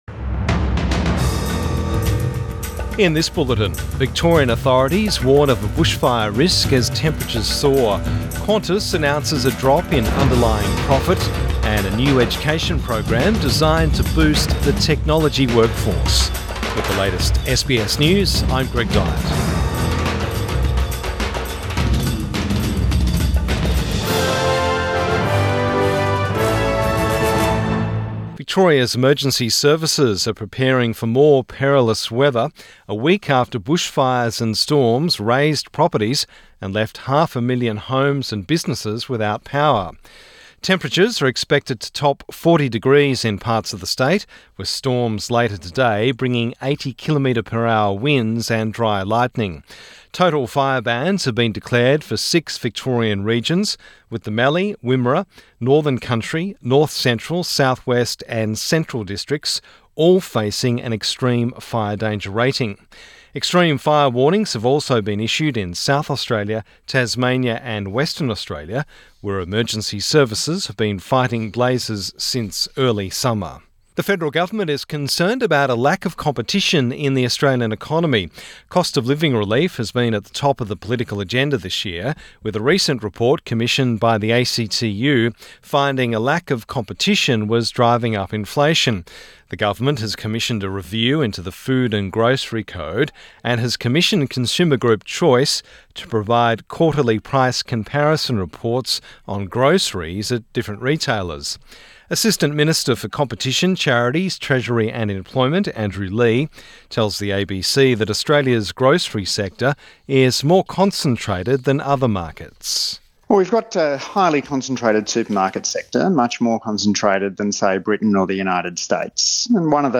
Midday News Bulletin 22 February 2024